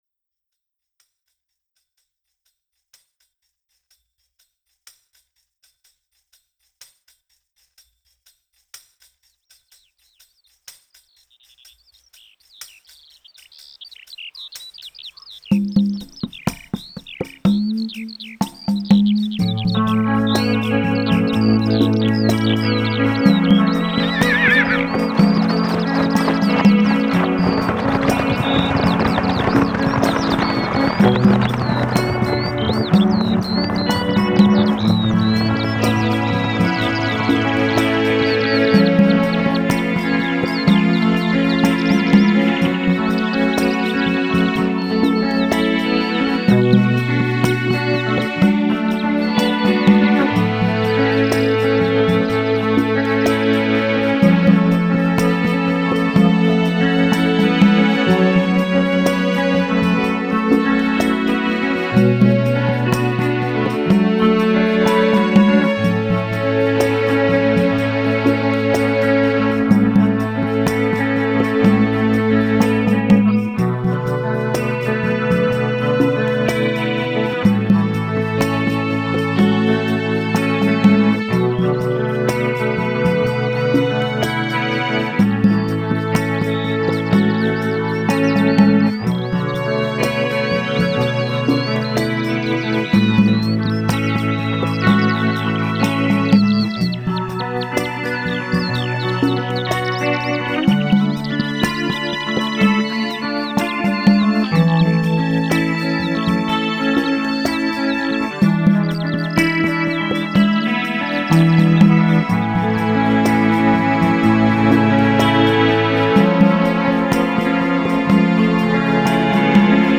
Genre: Ambient,Electronic.